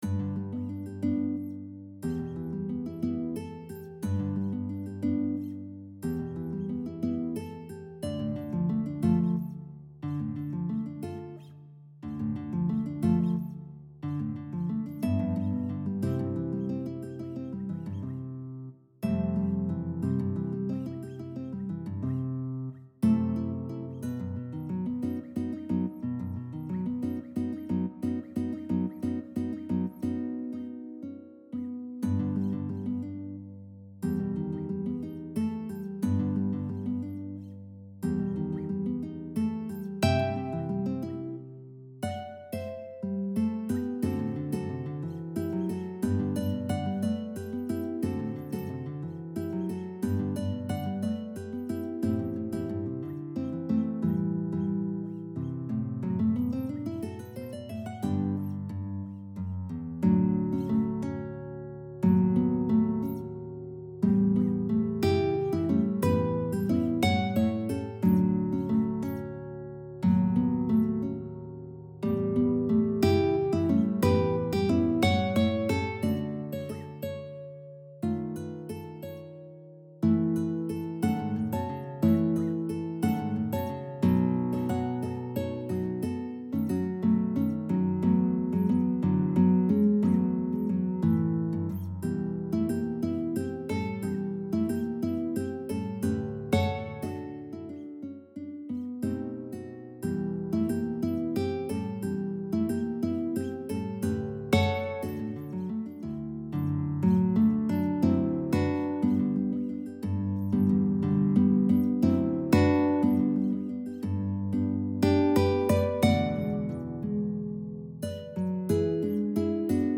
Digital recording